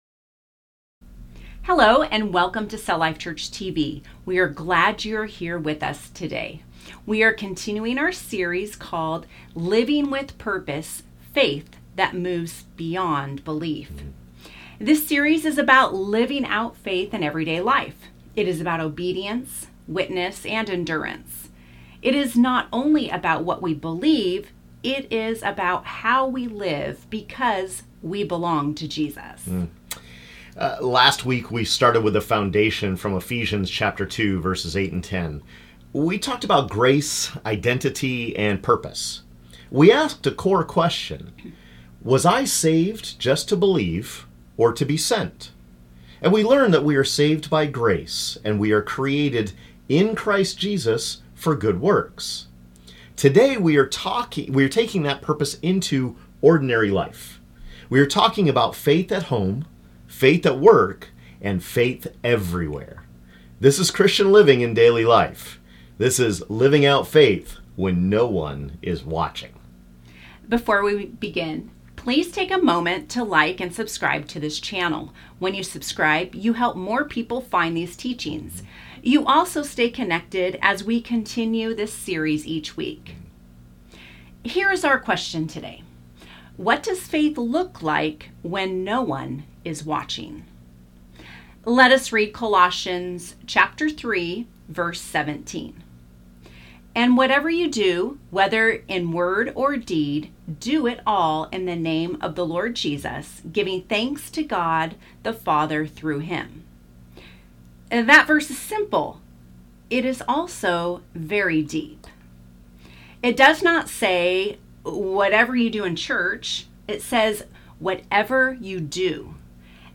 Colossians 3:17 shows that living out faith is meant to shape our words and actions in ordinary places. In this Week 2 teaching, we focus on Christian living in daily life at home, at work, and everywhere we go.